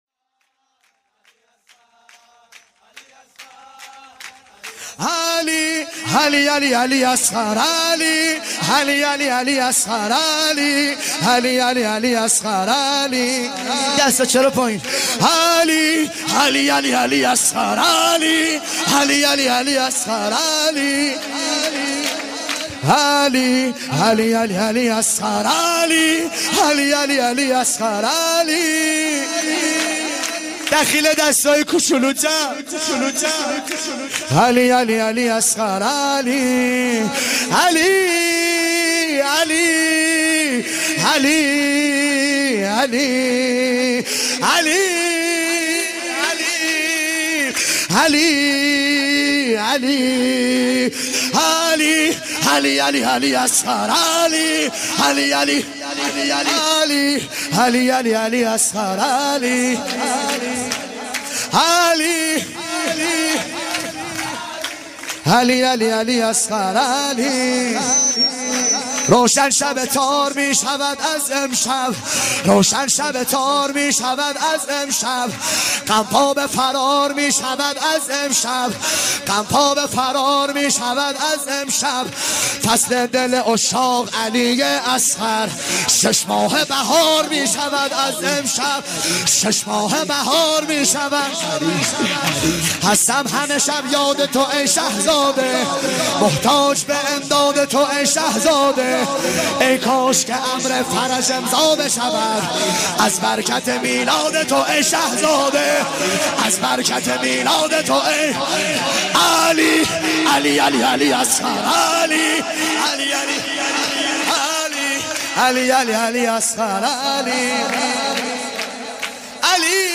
5. مداحی و مولودی
مولودی